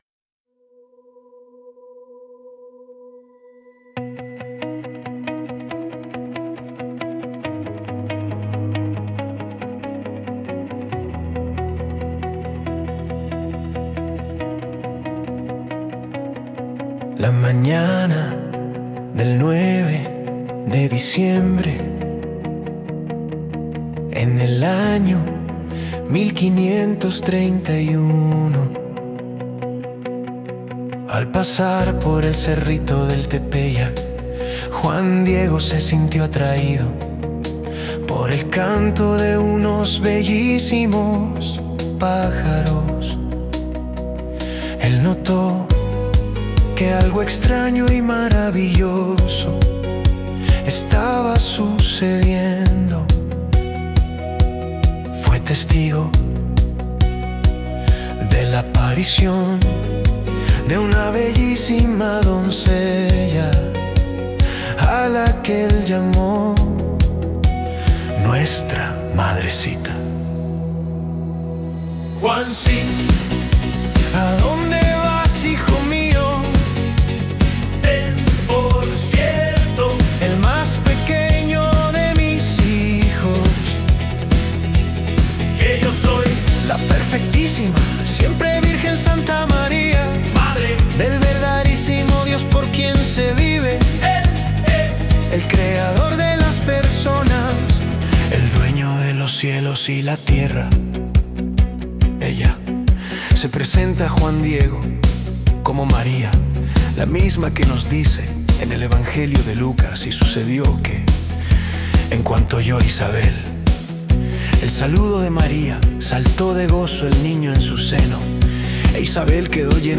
Escucha Música Muestra 1: capas 3D